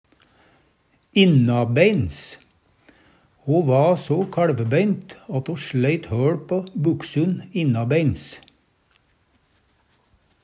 innabeins - Numedalsmål (en-US)